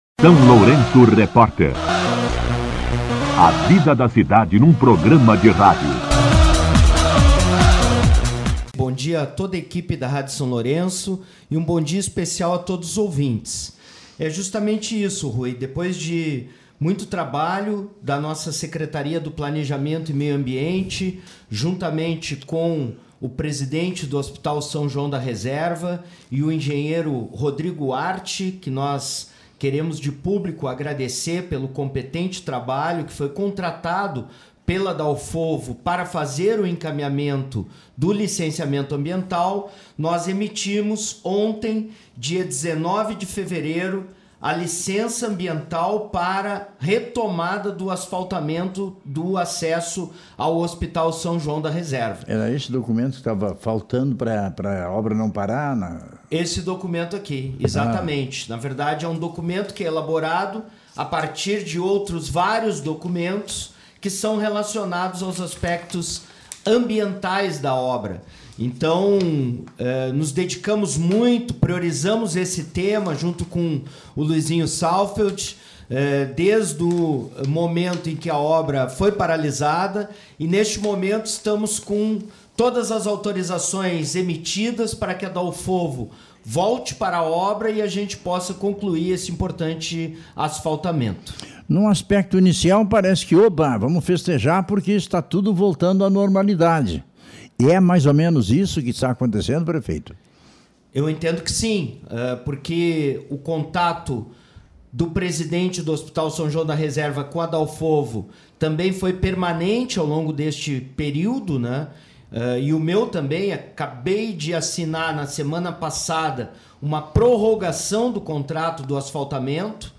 Entrevista com o Prefeito Zelmute Marten
O prefeito Zelmute Marten concedeu entrevista ao SLR RÁDIO na manhã desta sexta-feira (20) e confirmou a retomada das obras de asfaltamento da estrada da Reserva. Segundo o chefe do Executivo, a licença ambiental foi regularizada e toda a documentação necessária está em dia para a continuidade do projeto.